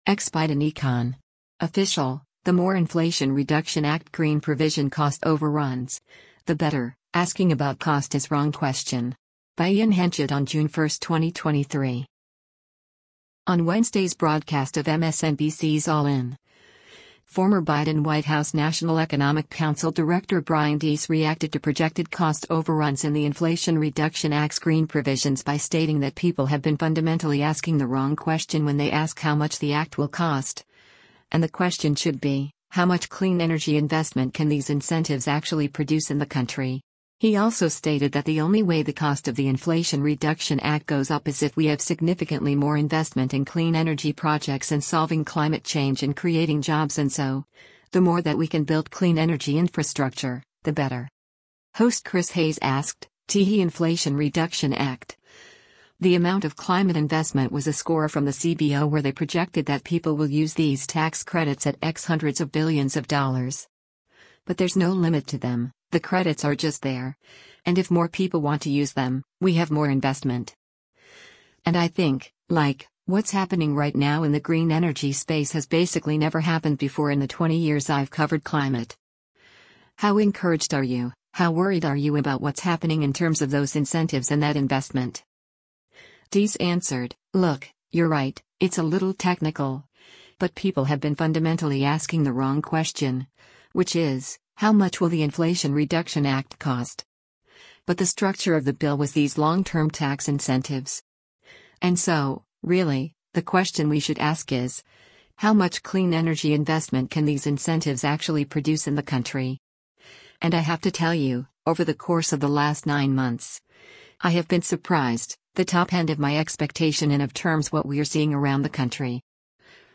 Host Chris Hayes asked, “[T]he Inflation Reduction Act, the amount of climate investment was a score from the CBO where they projected that people will use these tax credits at x hundreds of billions of dollars.